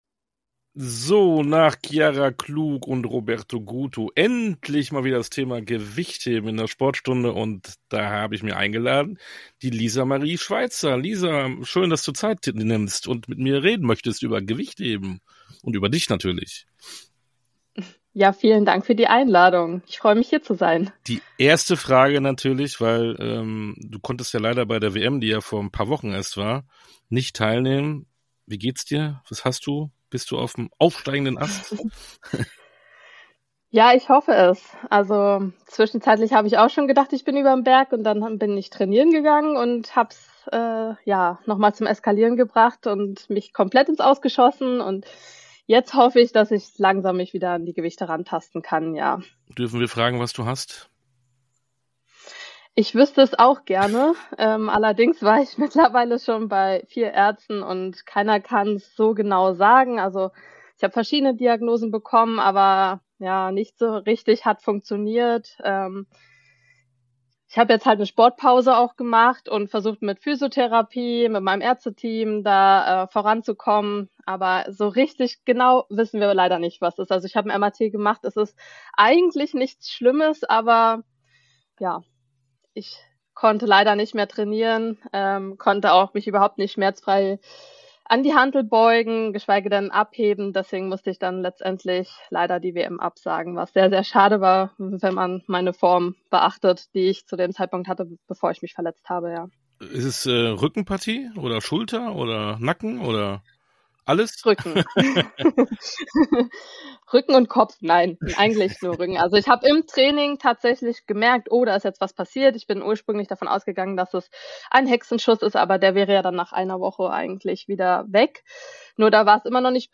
Interviews in voller Länge Podcast